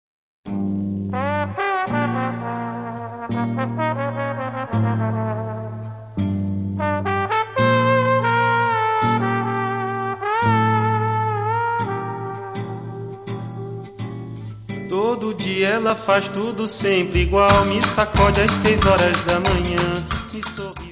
: stereo; 12 cm + Livro
Music Category/Genre:  World and Traditional Music